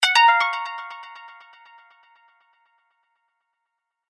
короткие